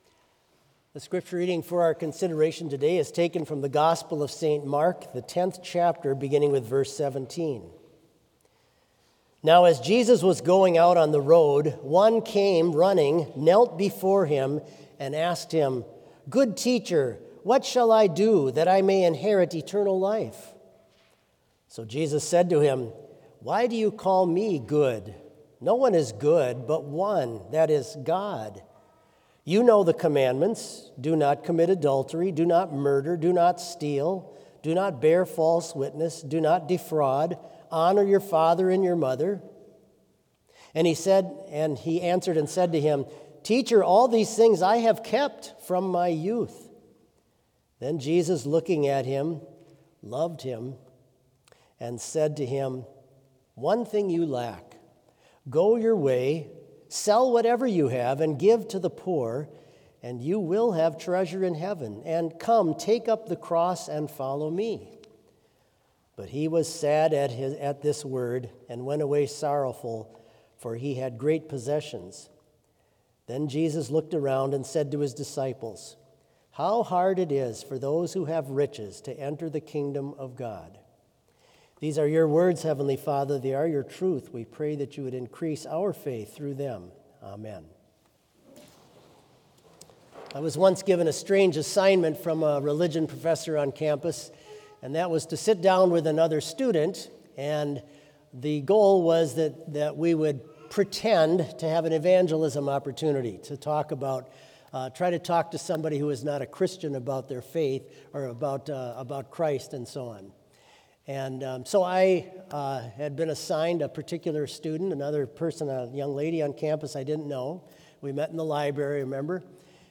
Complete service audio for Chapel - Friday, October 24, 2025